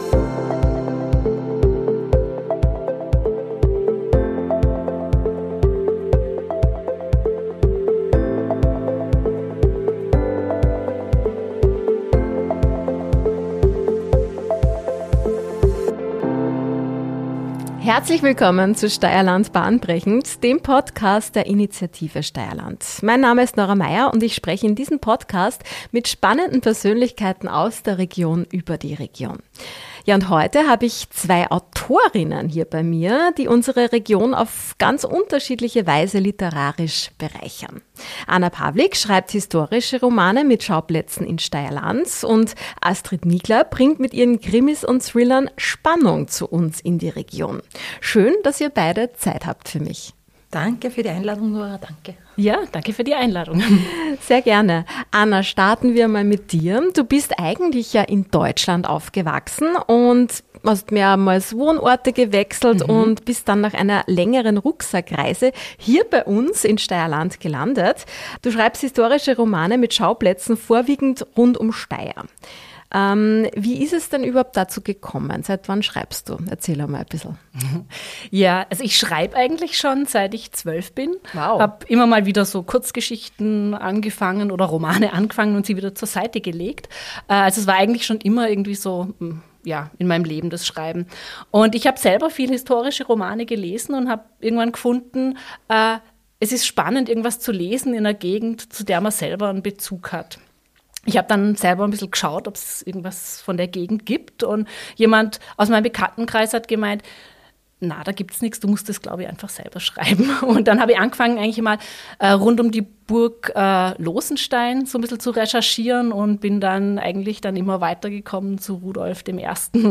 Folge 32: Plot Twist in steyrland - im Gespräch mit den Autorinnen